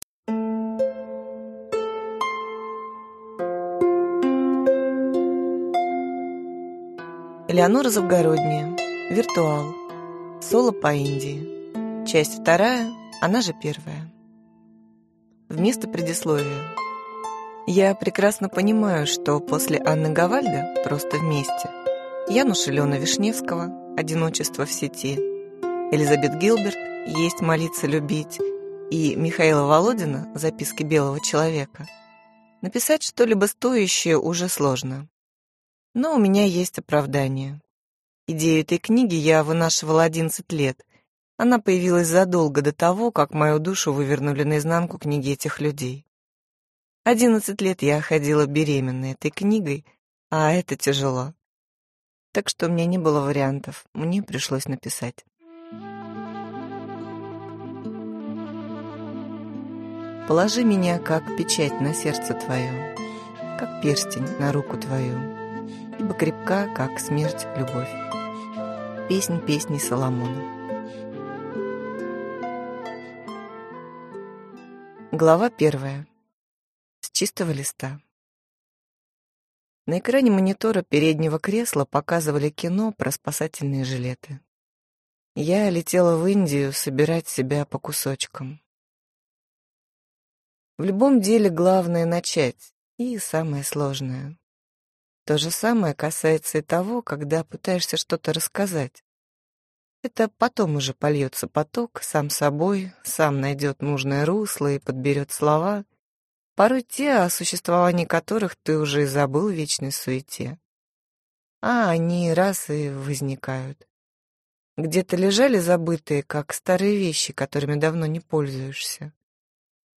Аудиокнига Виртуал. Соло по Индии. Часть 2, она же – первая | Библиотека аудиокниг